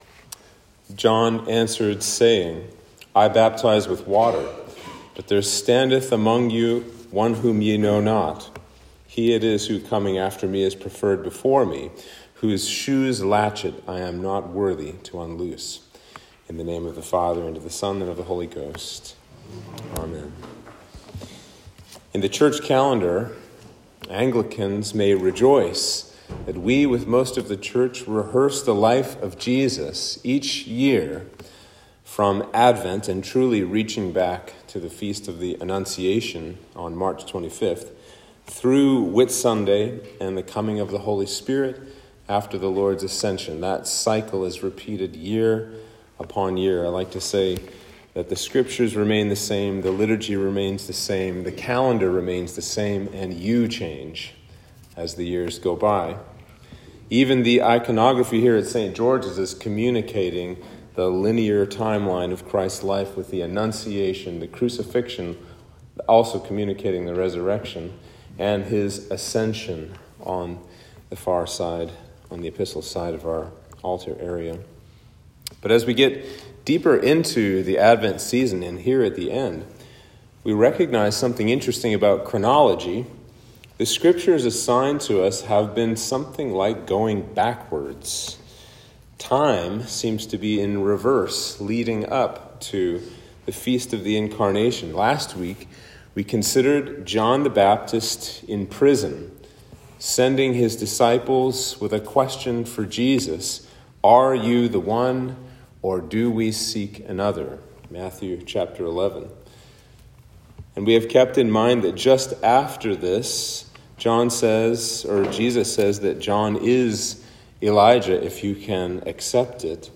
Sermon for Advent 4